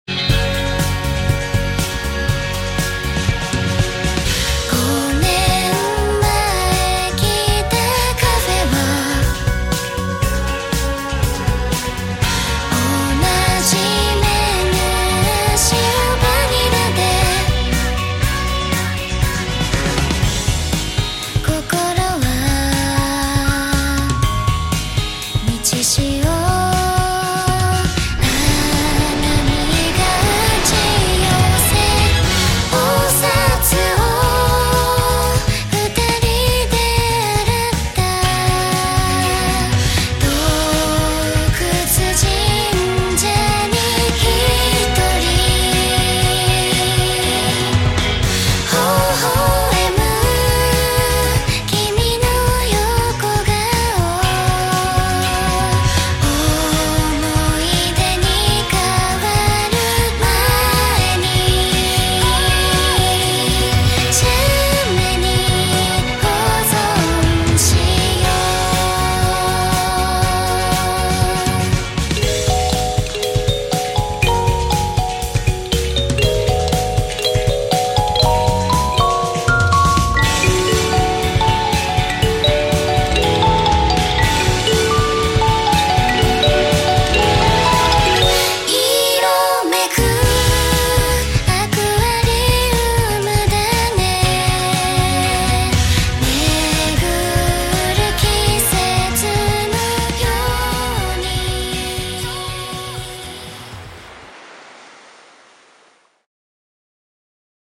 Kalimba
カリンバ